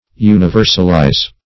Universalize \U`ni*ver"sal*ize\, v. t. [imp.